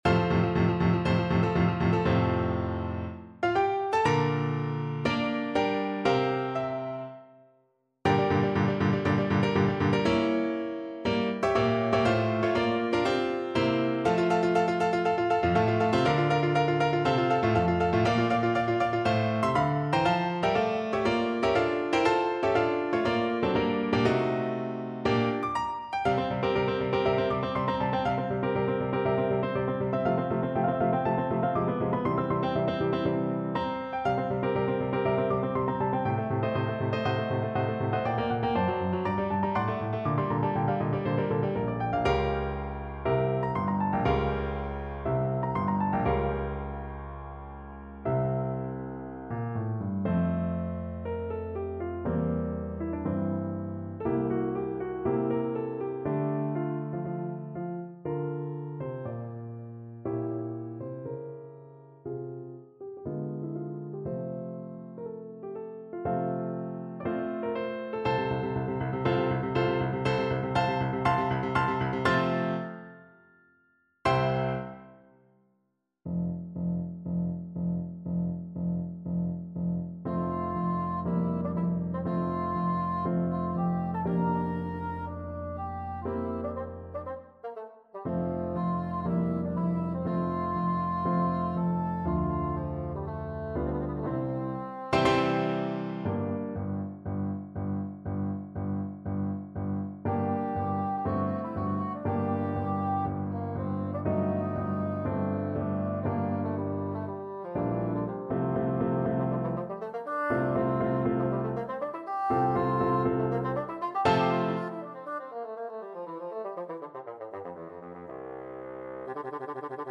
Bassoon
Allegro ma non troppo (View more music marked Allegro)
4/4 (View more 4/4 Music)
F major (Sounding Pitch) (View more F major Music for Bassoon )
Classical (View more Classical Bassoon Music)